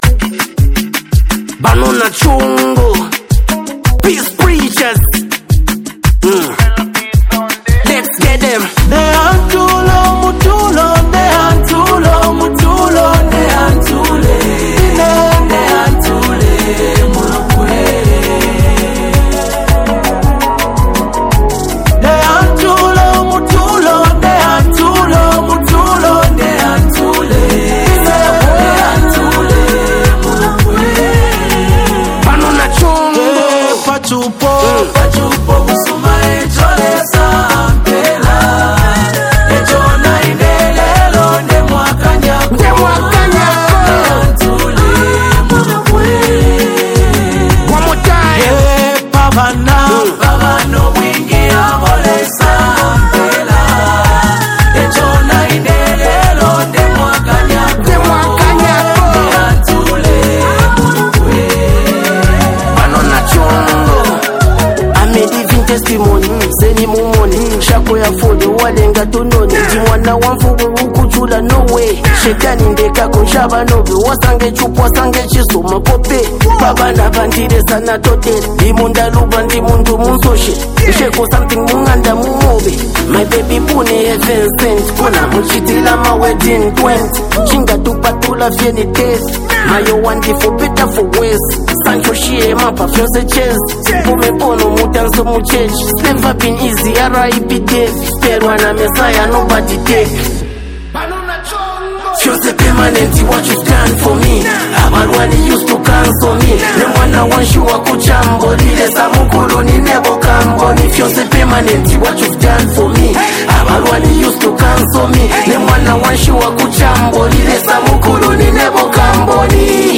rap sensation and songwriter.
gospel sensation artists.